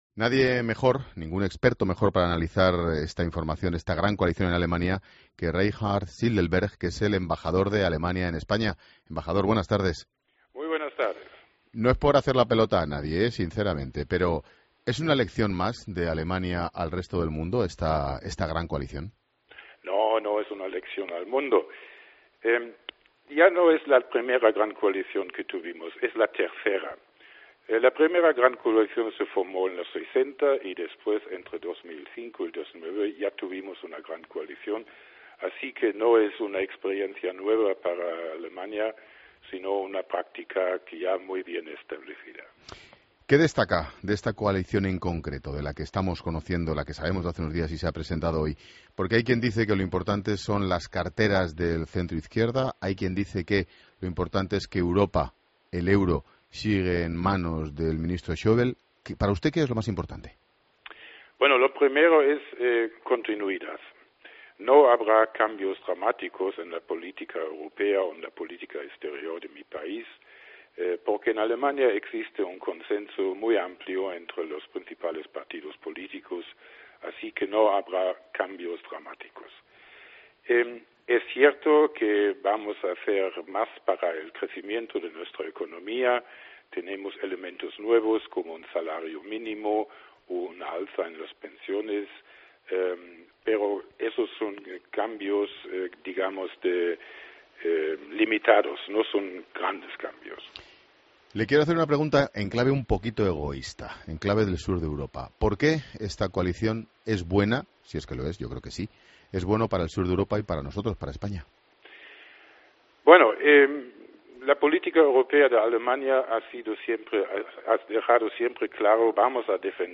Escucha la entrevista al embajador alemán en España en Mediodía COPE